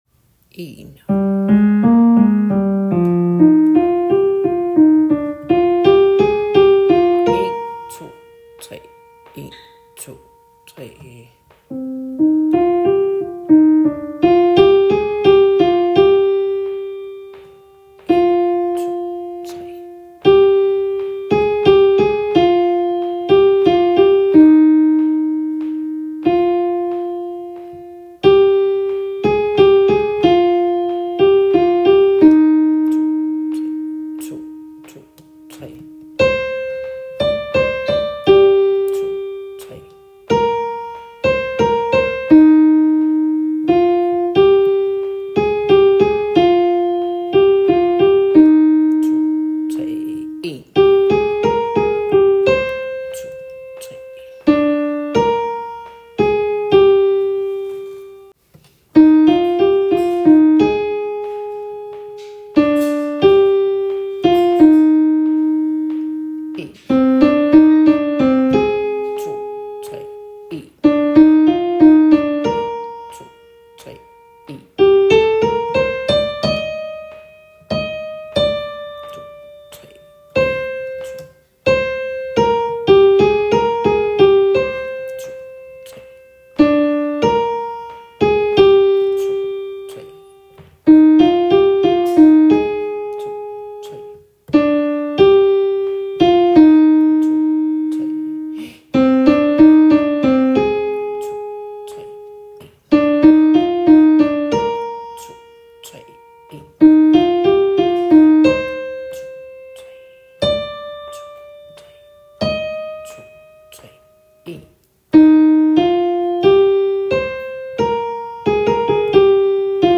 Kun koret